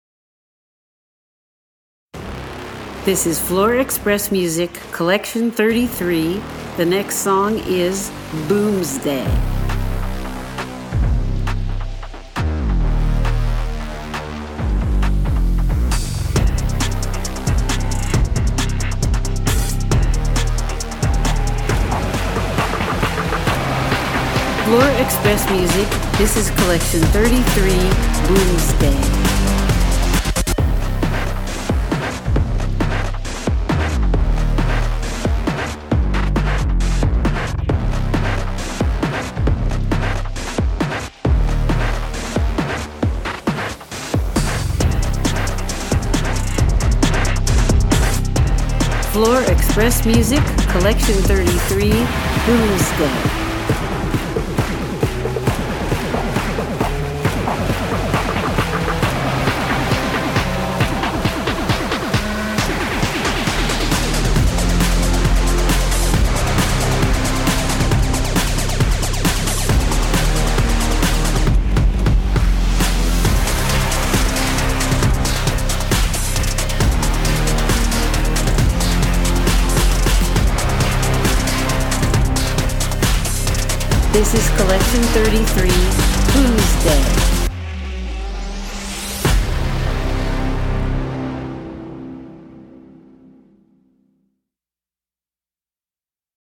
Gymnastics Floor Music
• K-Pop
• Contemporary